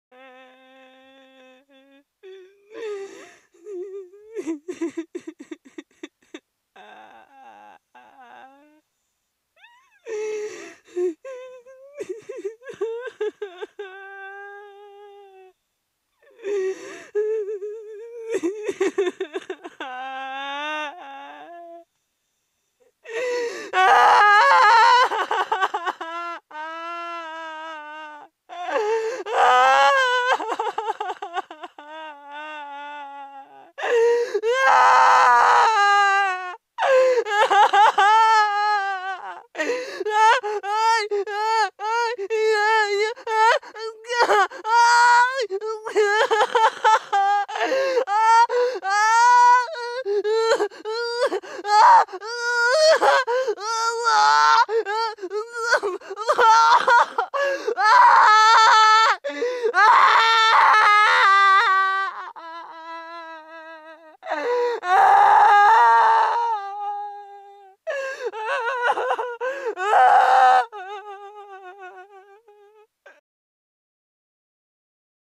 دانلود صدای گریه 4 از ساعد نیوز با لینک مستقیم و کیفیت بالا
جلوه های صوتی